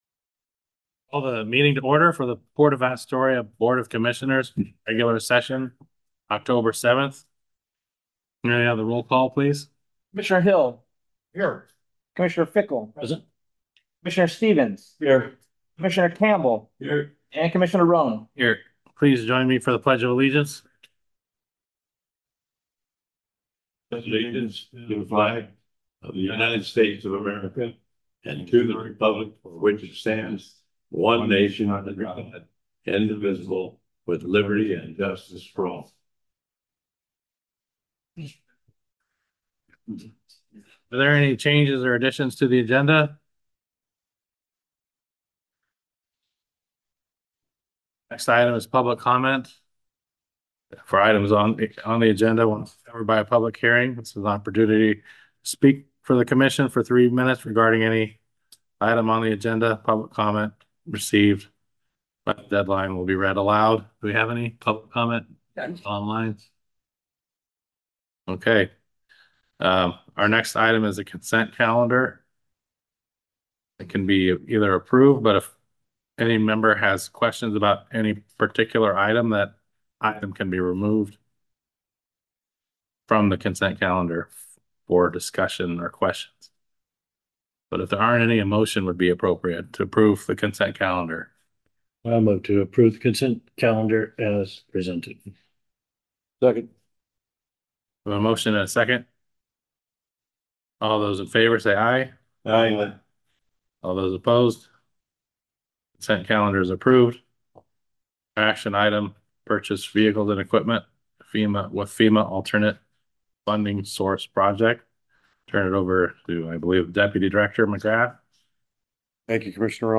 Commission Meeting
422 Gateway Avenue Suite 100, Astoria, OR, at 4 PM